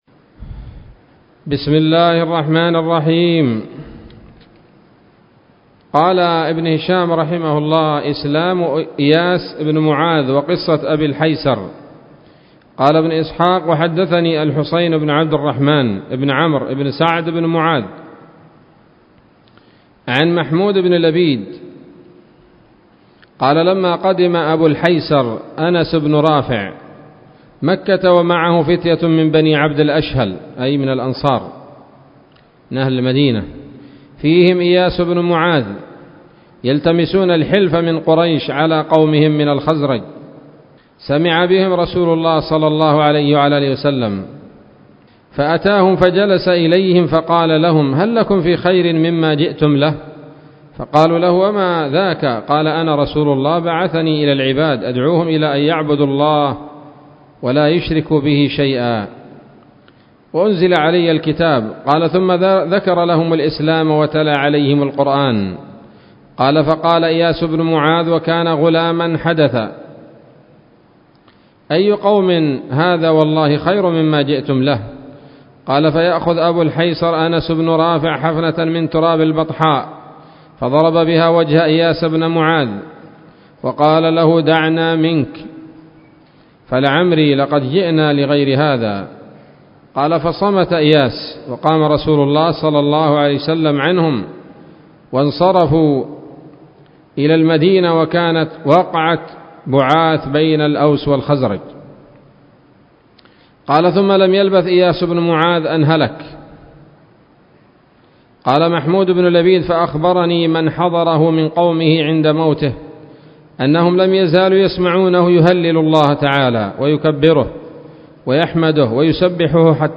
الدرس التاسع والخمسون من التعليق على كتاب السيرة النبوية لابن هشام